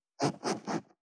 421,ジッパー,チャックの音,洋服関係音,ジー,バリバリ,カチャ,ガチャ,シュッ,パチン,
ジッパー効果音洋服関係